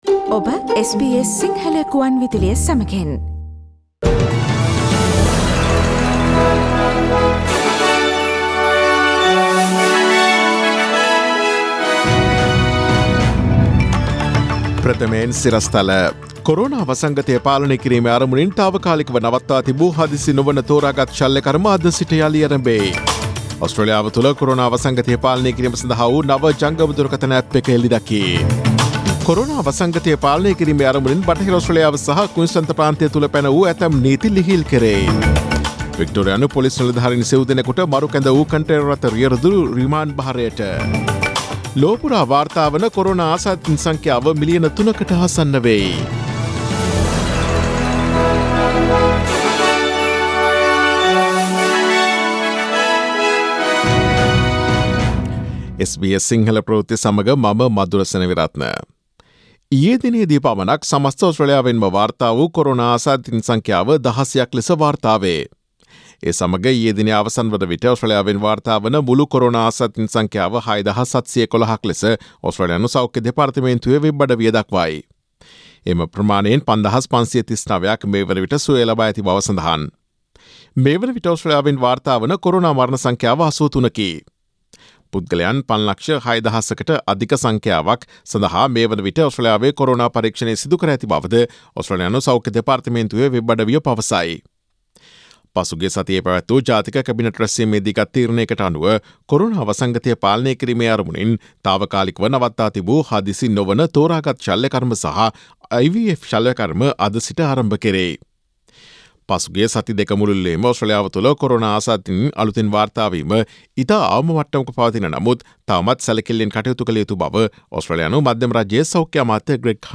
Daily News bulletin of SBS Sinhala Service: Monday 27 April 2020